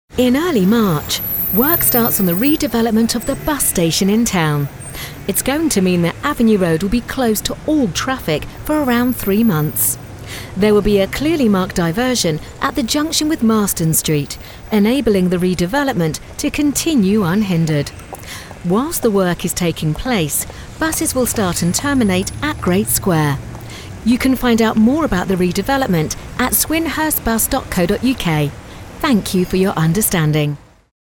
Erklärvideos
Meine Stimme wird als nahbar, voll, selbstsicher, sanft, selbstbewusst und vertrauenswürdig beschrieben.
Professionelle Gesangskabine mit kabelloser Tastatur und Maus.
Focsurite Scarlett 2i2, D2 Synco-Richtmikrofon und Twisted-Wave-Aufnahmesoftware.
Niedrig